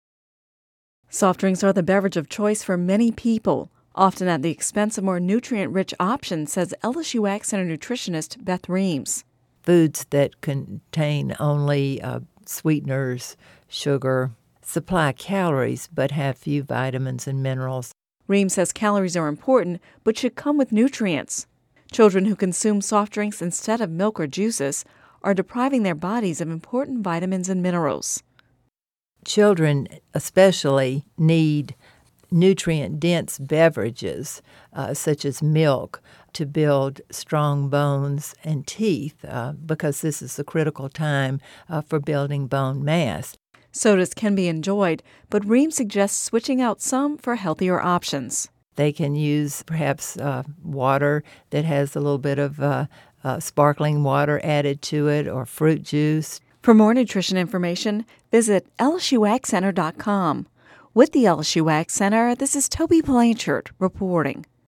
(Radio News 10/25/10) Soft drinks are the beverage of choice for many people -- often at the expense of more nutrient-rich options